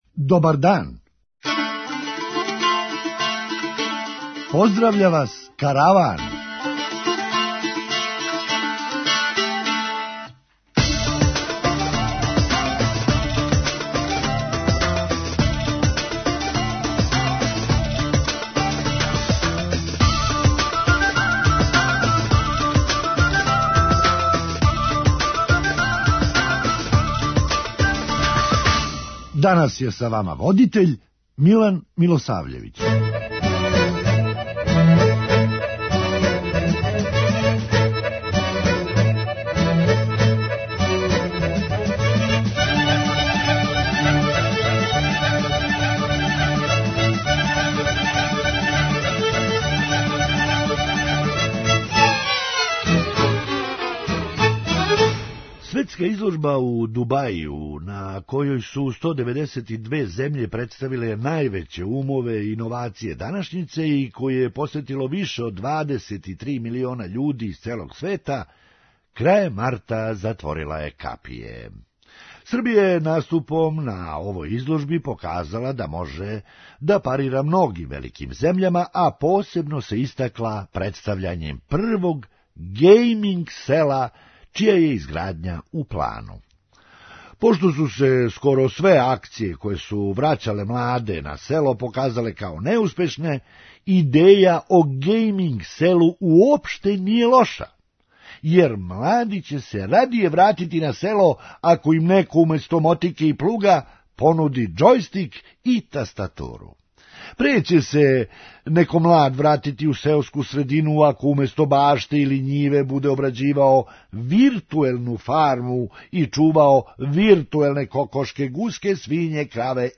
Хумористичка емисија
Али, ако може купљена диплома да буде замена за стечену, може и матура да буде замена за пријемни испит. преузми : 8.92 MB Караван Autor: Забавна редакција Радио Бeограда 1 Караван се креће ка својој дестинацији већ више од 50 година, увек добро натоварен актуелним хумором и изворним народним песмама.